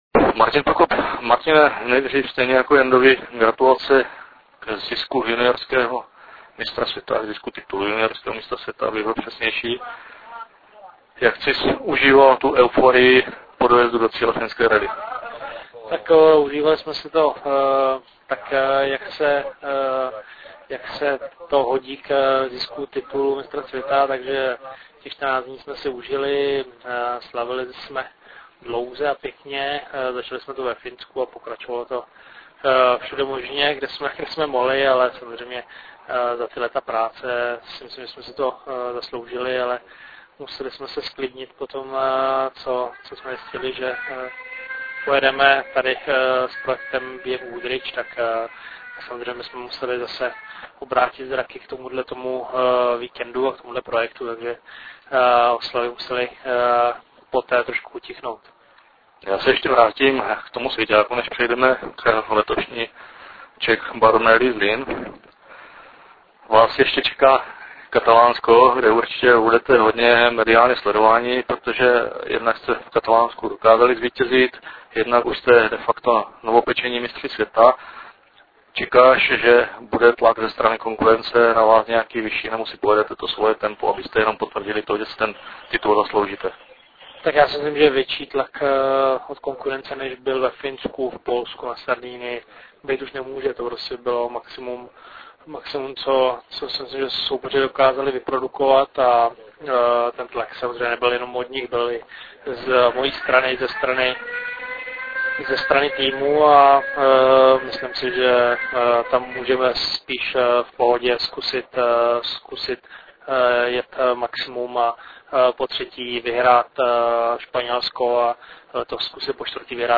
Rozhovor s Martinem Prokopem (MP3)